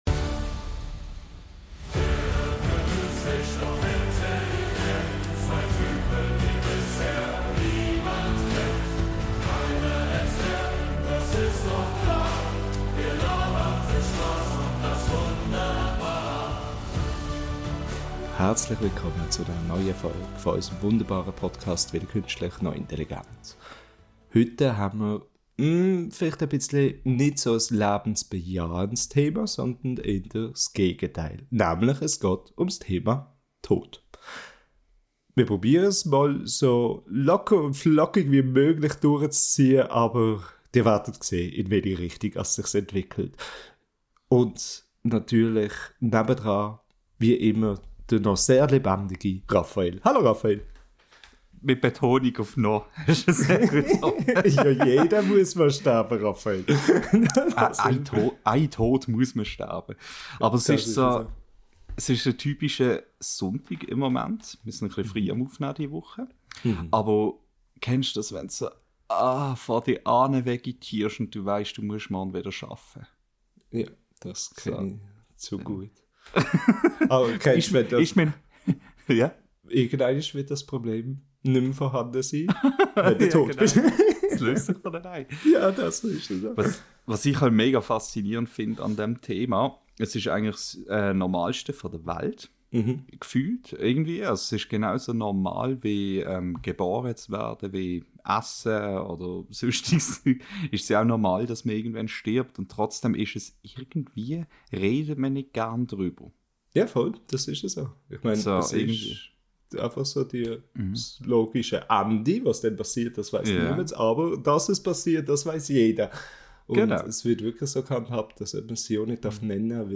In dieser Episode unseres schweizerdeutschen Podcasts sprechen wir über ein eher schweres Thema – den Tod.